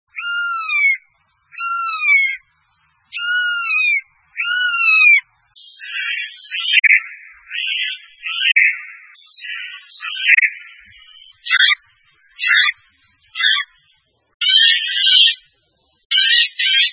En cliquant ici vous entendrez le chant du geai des chênes qui imite le chant de la buse, de la corneille, etc...
Le Geai des chênes